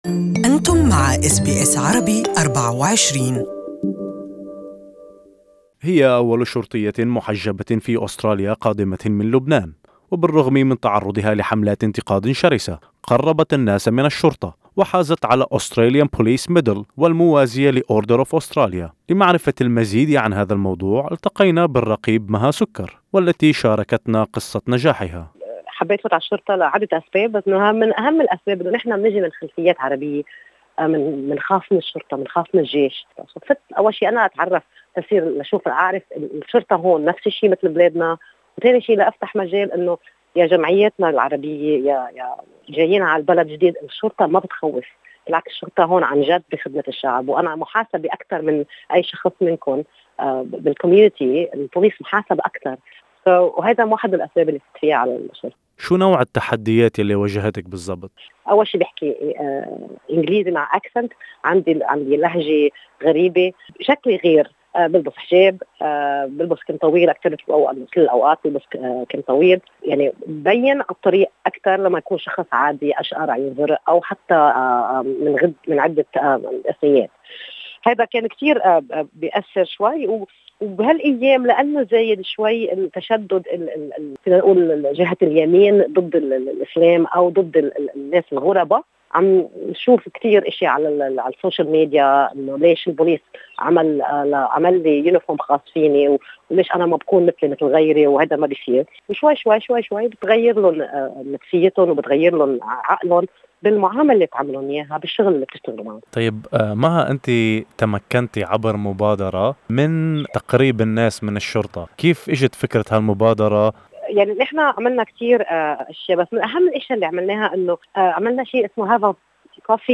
لمعرفة المزيد استمعوا إلى اللقاء أعلاه.